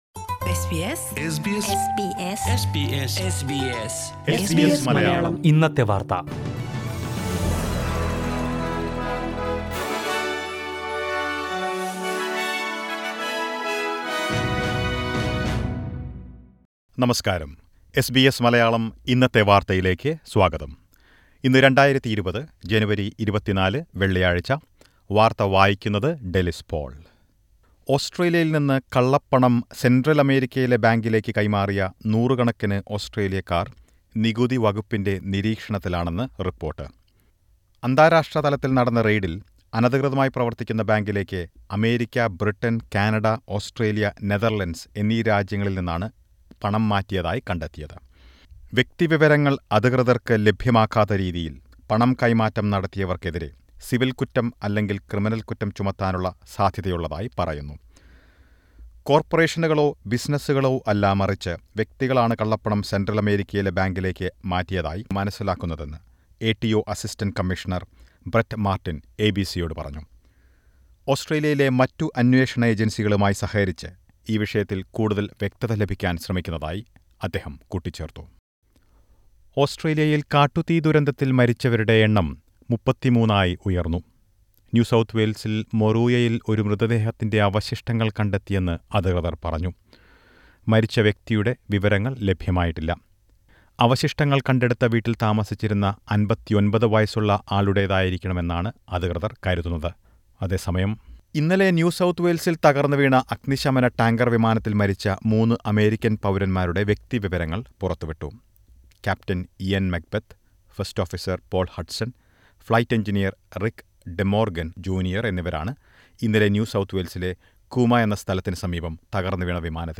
2020 ജനുവരി 24ലെ ഓസ്ട്രേലിയയിലെ ഏറ്റവും പ്രധാന വാര്‍ത്തകള്‍ കേള്‍ക്കാം...
news2401.mp3